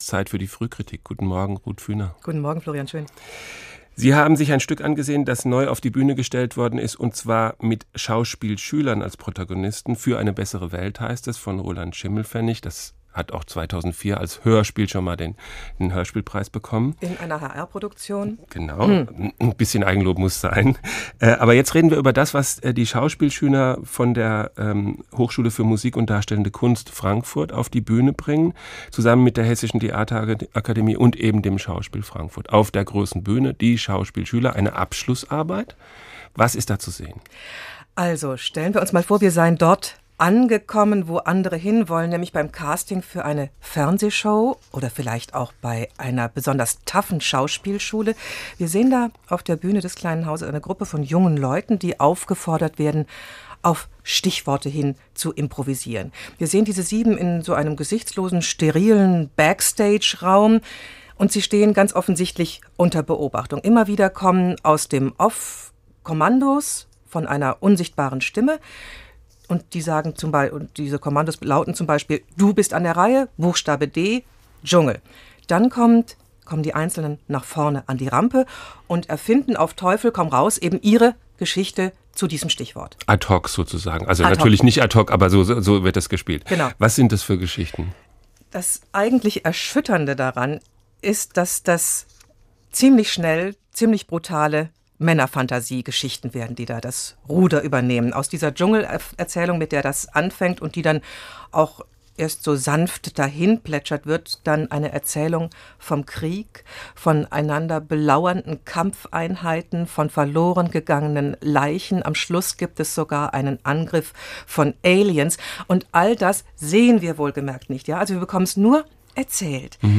HR2-fruehkritik am 23.03.2007 [mp3] [7.393 KB] />